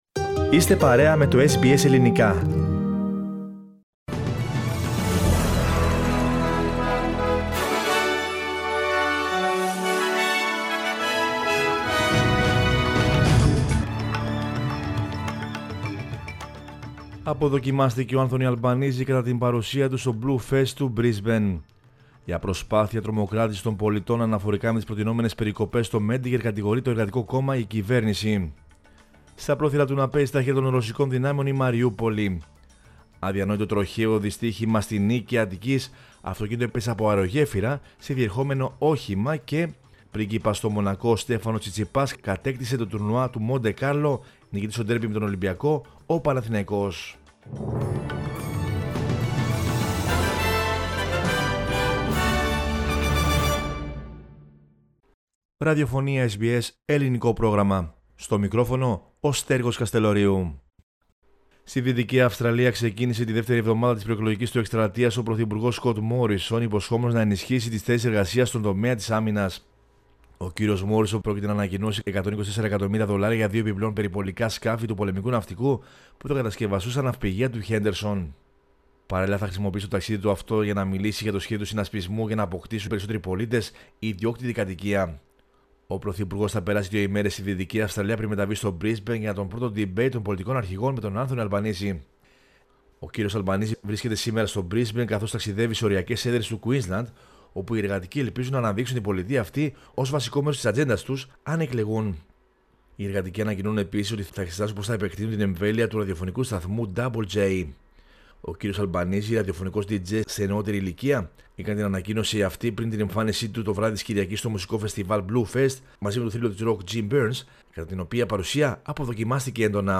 Δελτίο Ειδήσεων Δευτέρα 18.04.22
News in Greek.